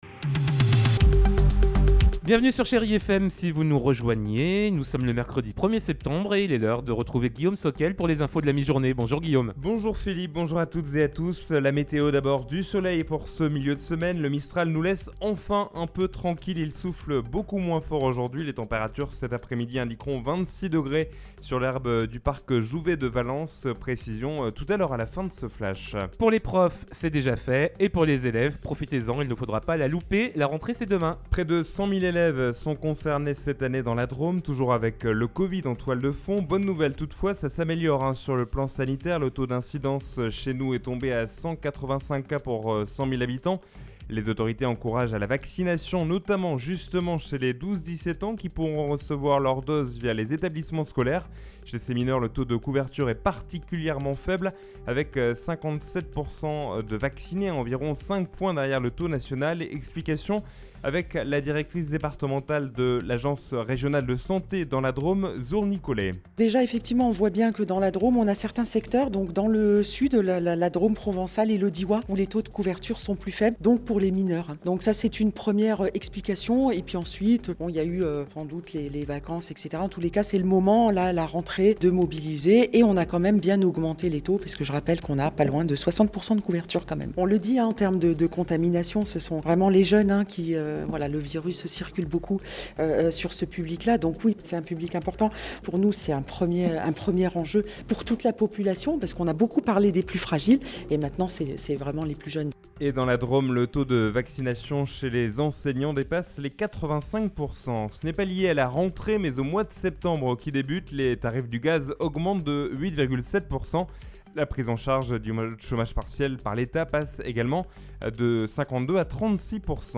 in Journal du Jour - Flash